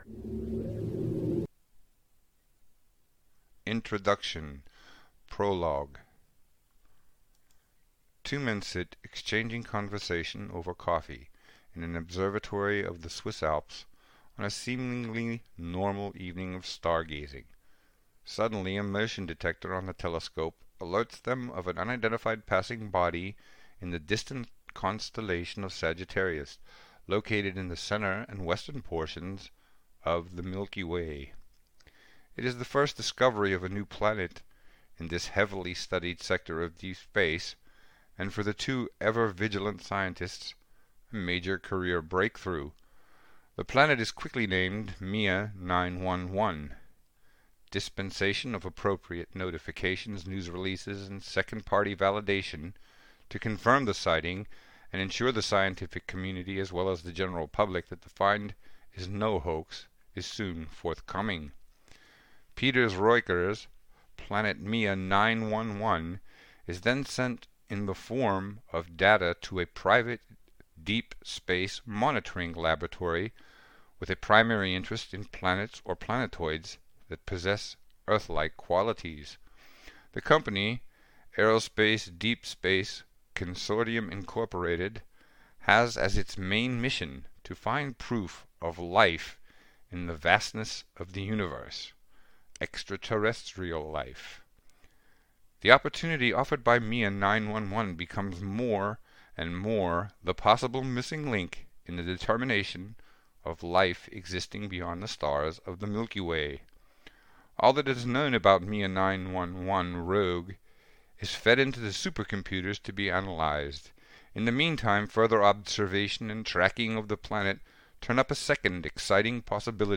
DOWNLOAD Play Sample intro.mp3 00:00 / 03:45 Audiobook Format MP3 Free sample Any Problems?